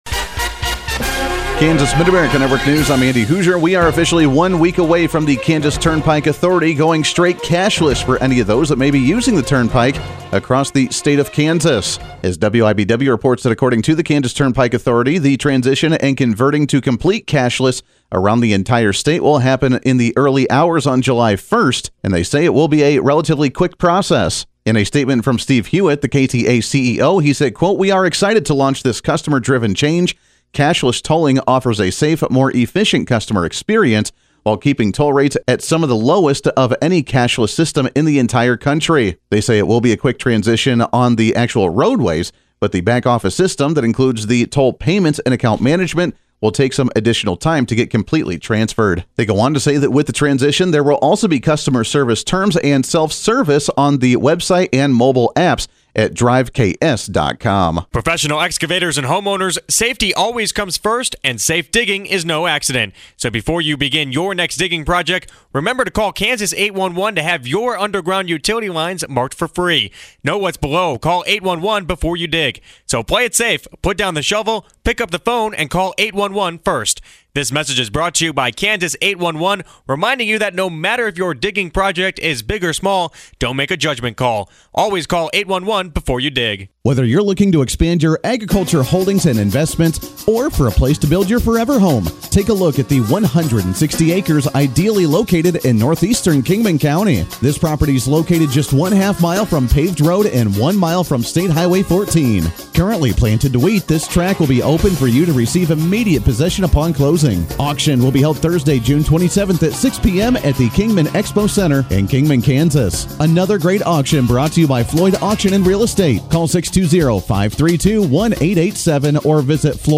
Due to a power outage from overnight storms in Marysville, this is KNDY News in Brief, covering Kansas & Nebraska News, Markets, Ag News and Kansas Sports. Audio courtesy of the Mid America Ag Network.